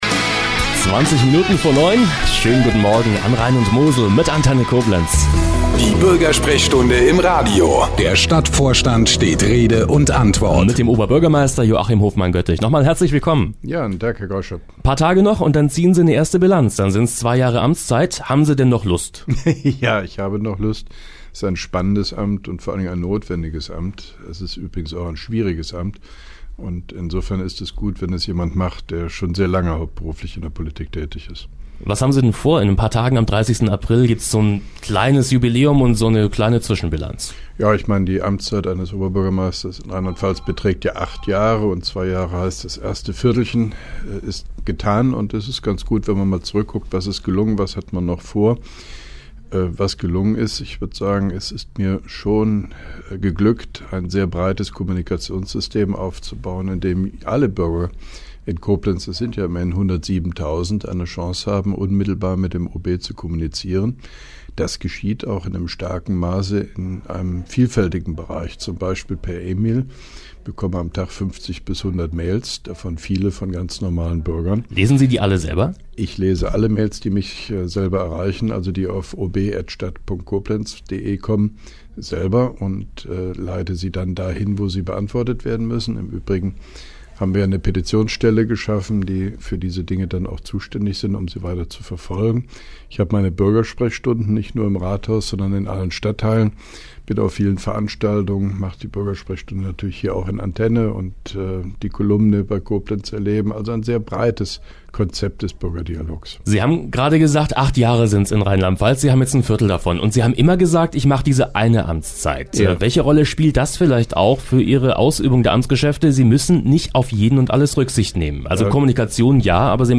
(2) Koblenzer Radio-Bürgersprechstunde mit OB Hofmann-Göttig 24.04.2012